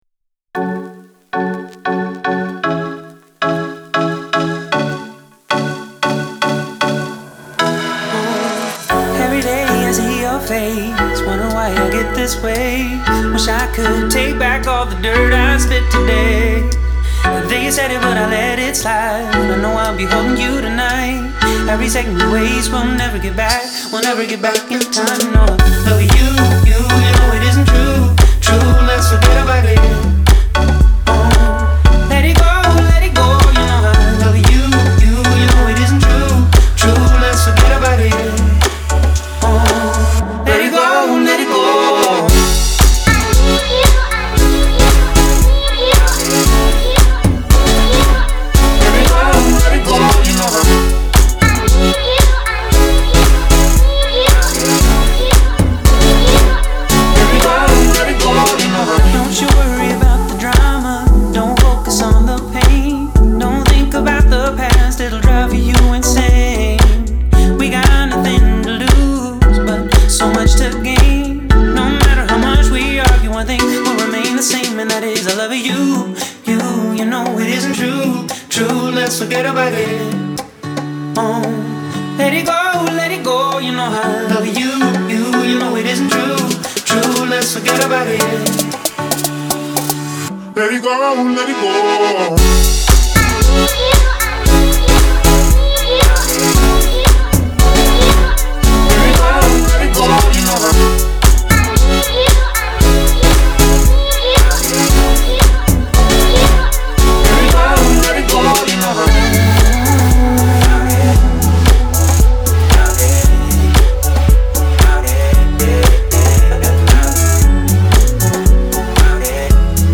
German Producer
American singer songwriter